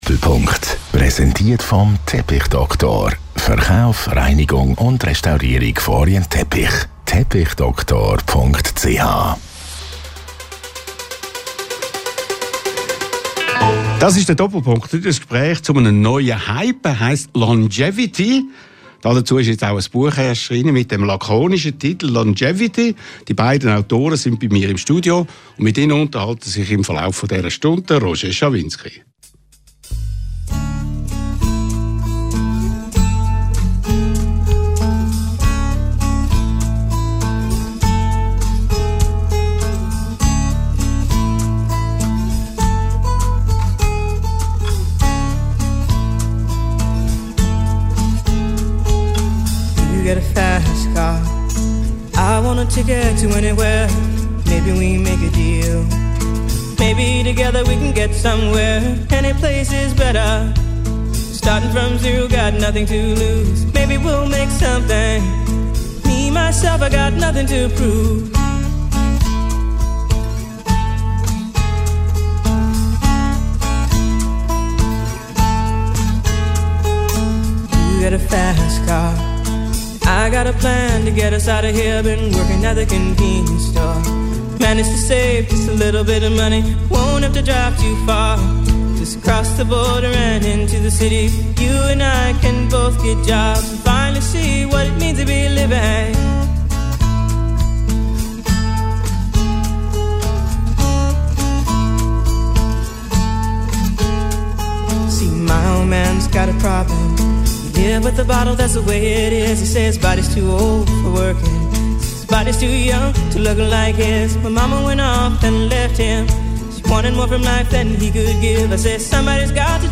Radio 1-Chef Roger Schawinski spricht mit den beiden Autoren